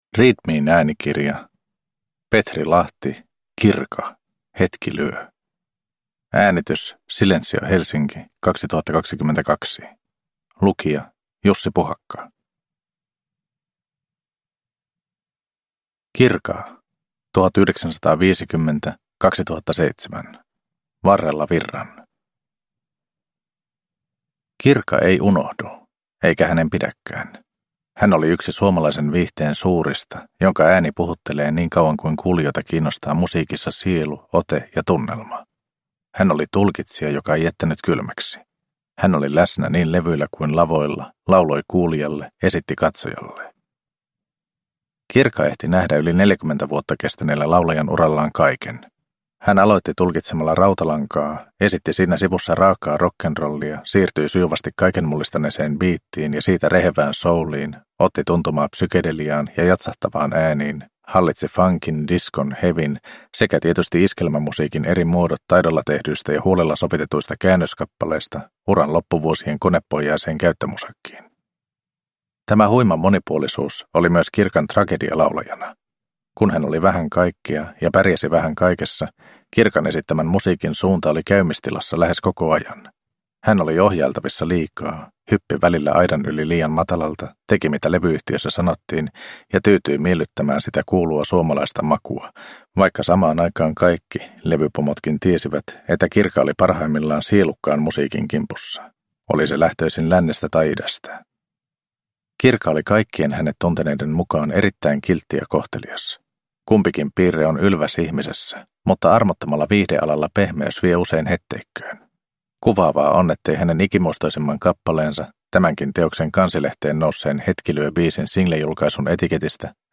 Kirka - Hetki lyö – Ljudbok